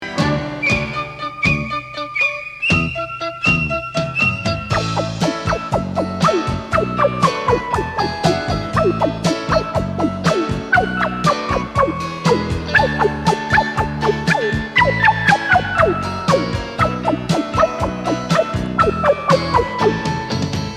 un générique qui me rappelle tant de souvenirs